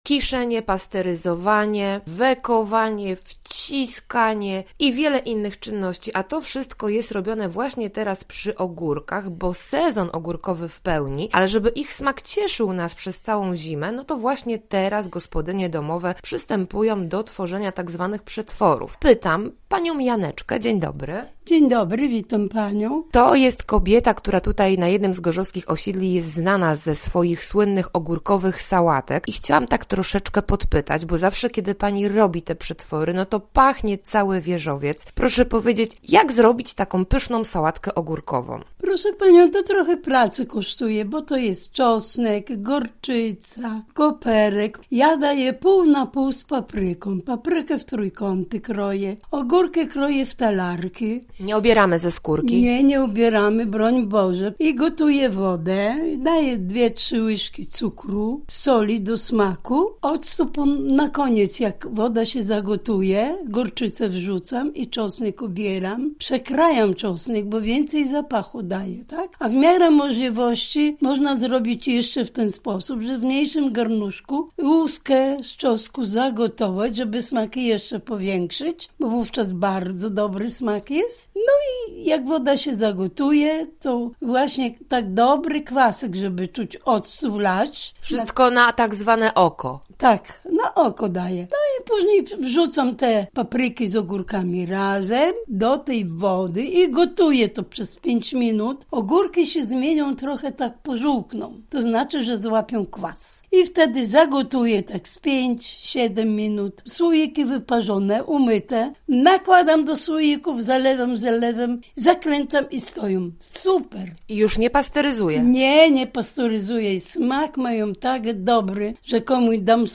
O tym wszystkim w rozmowie z gorzowską seniorką